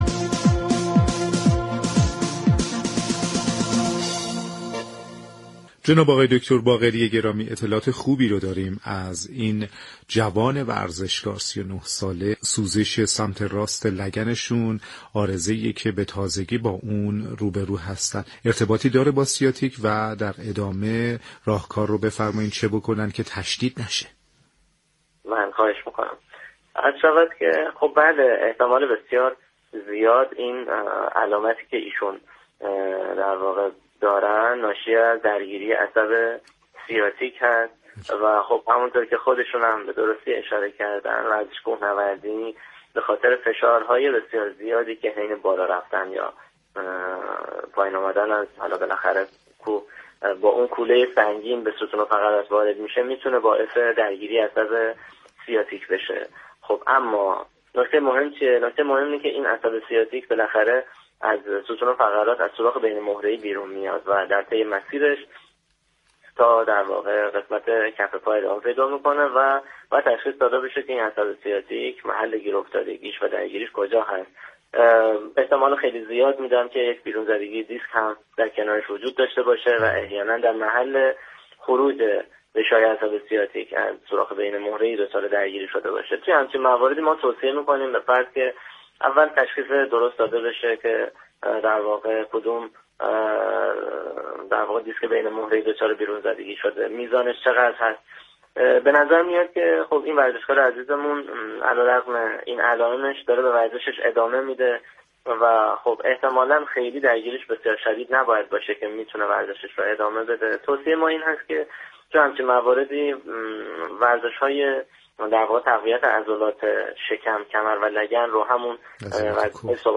/صوت آموزشی/